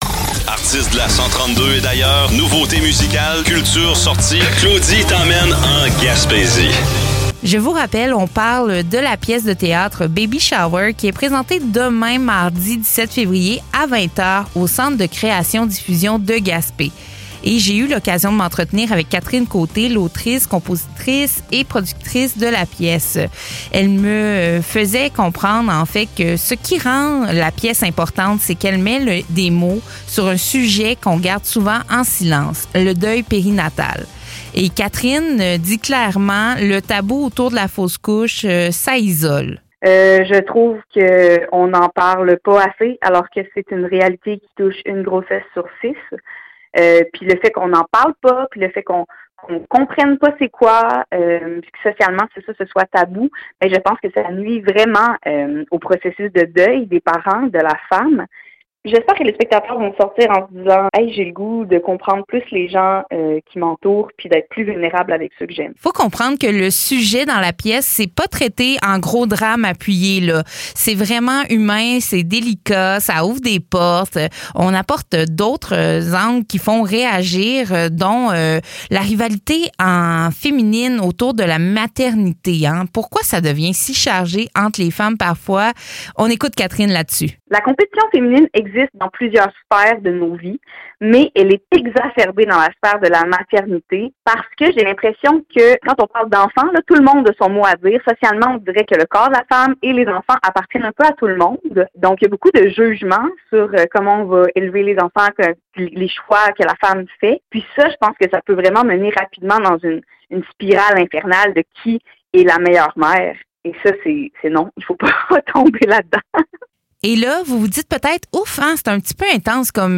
Ce que l’entrevue laisse surtout entendre, c’est ceci : Baby Shower n’est pas un spectacle qui écrase.